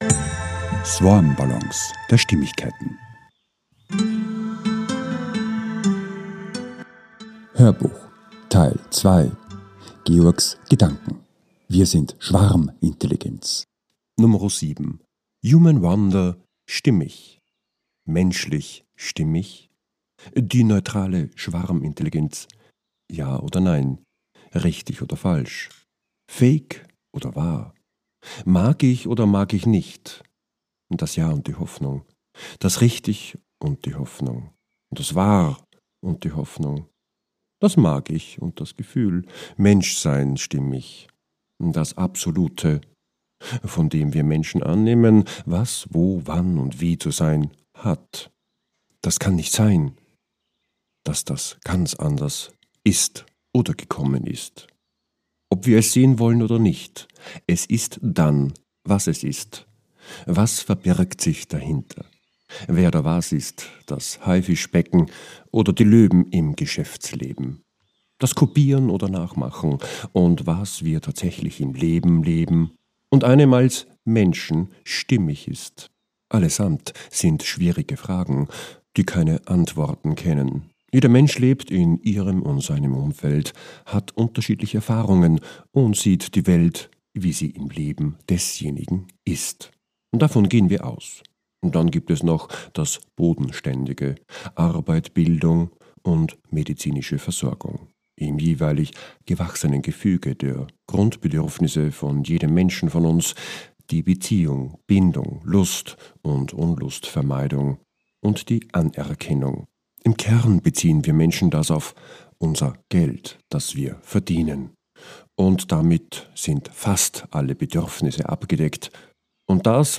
HÖRBUCH TEIL 2 - 07 - WIR SIND SCHWARMINTELLIGENZ 2 - HUMAN WONDER STIMMIG ~ SwarmBallons A-Z der Stimmigkeit Podcast